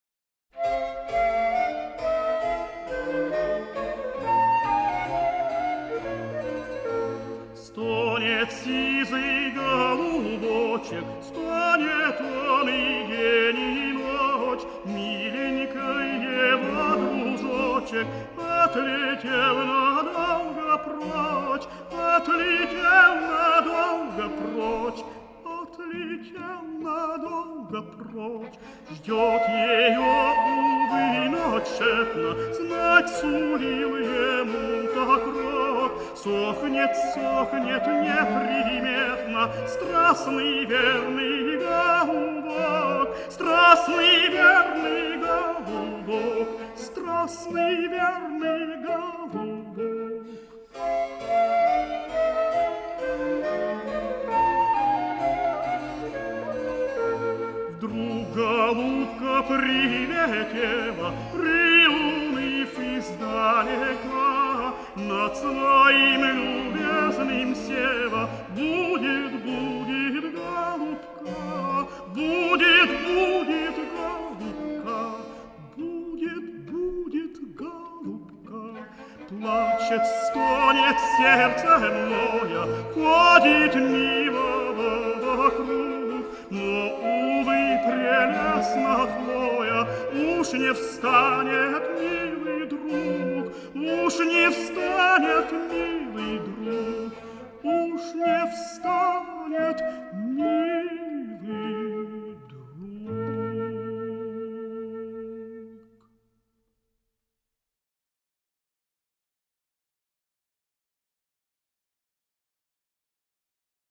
| (str. 1, 4, 6, 7) tenore Kostantin Pluzhnikov
soprano